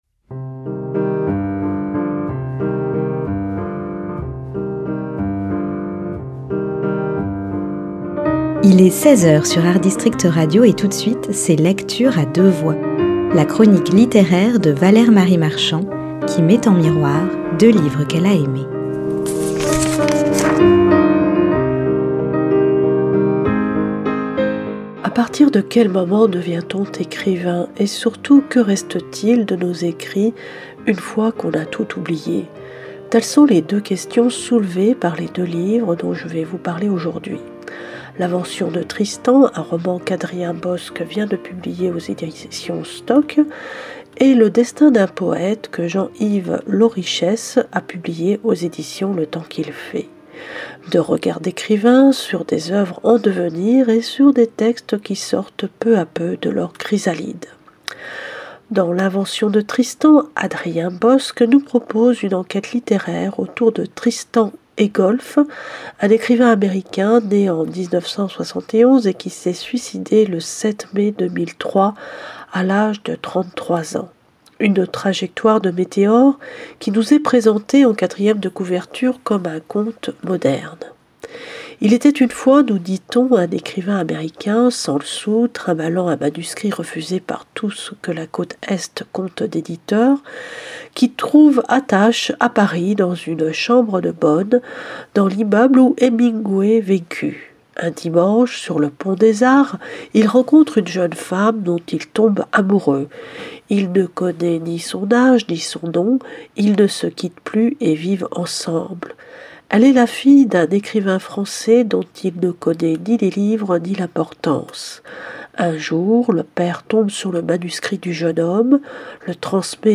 LECTURE A DEUX VOIX, mardi et vendredi à 10h et 16h. Chronique littéraire